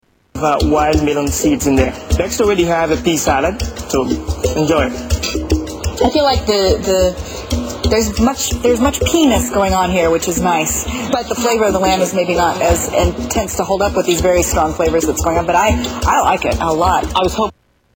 Tags: Media Freudian Slips News Newscasters Funny